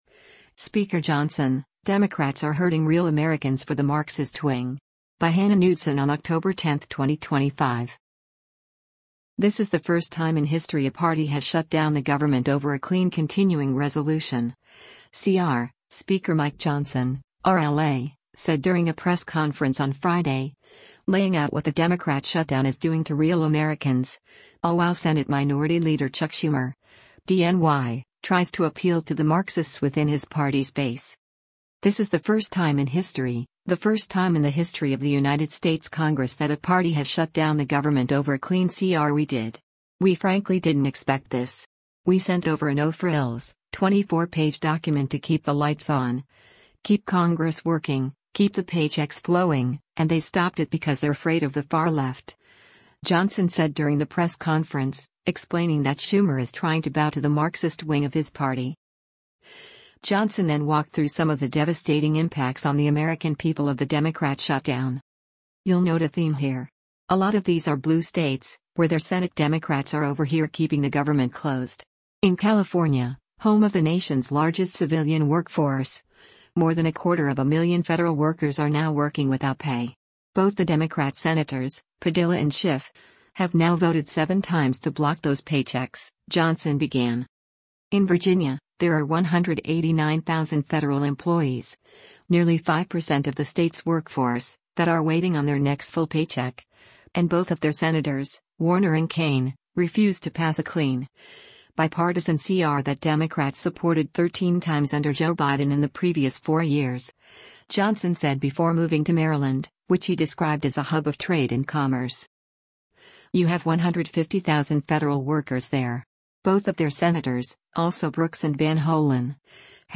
This is the first time in history a party has shut down the government over a clean continuing resolution (CR), Speaker Mike Johnson (R-LA) said during a press conference on Friday, laying out what the Democrat shutdown is doing to real Americans, all while Senate Minority Leader Chuck Schumer (D-NY) tries to appeal to the Marxists within his party’s base.